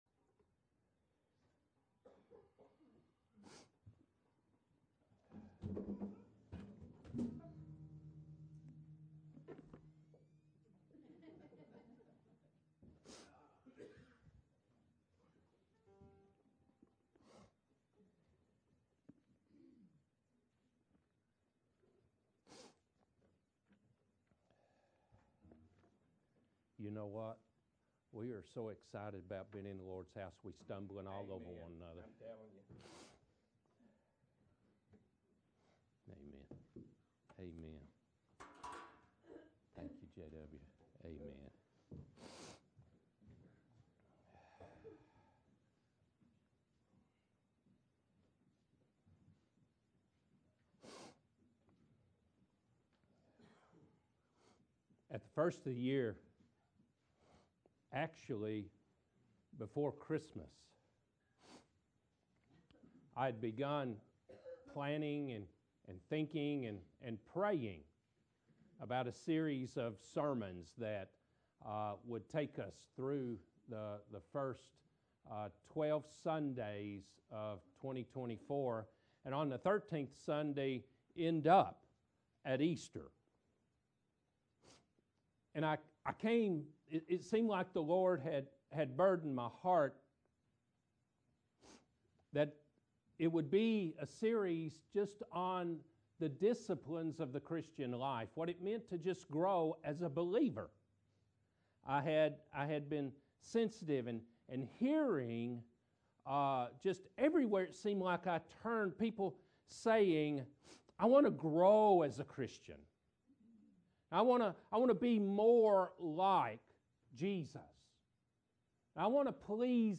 1-3 Service Type: Morning Worship « The Gift Of Grace & Forgiveness Spiritual Disciplines